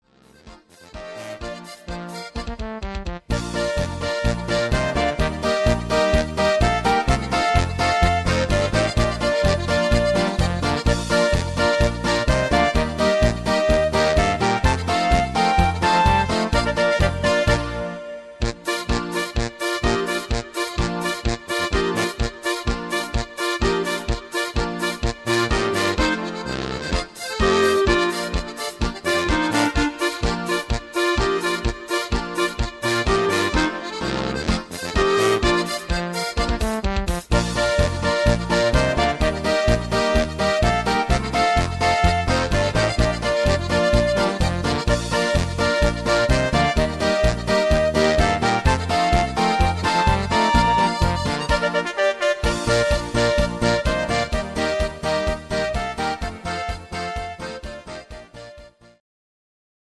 Oberkrainer Sound